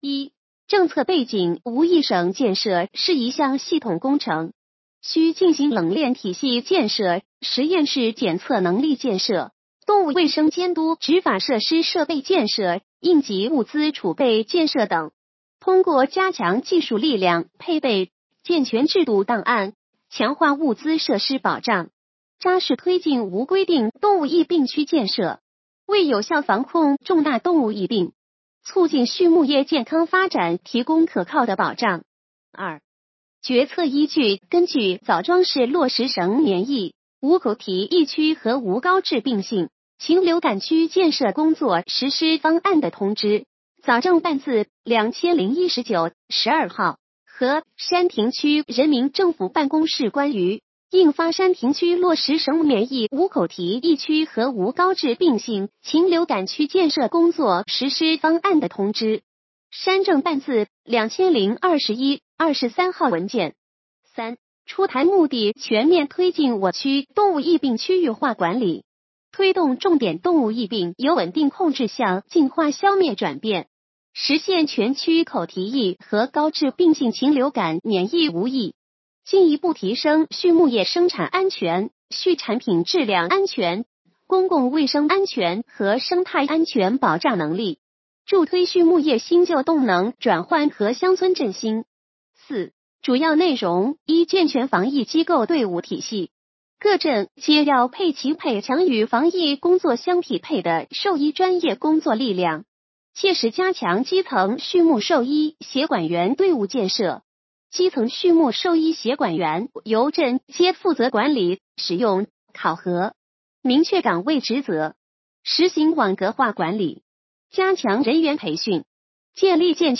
语音解读：山亭区人民政府办公室关于印发山亭区落实省免疫无口蹄疫区和无高致病性禽流感区建设工作实施方案的通知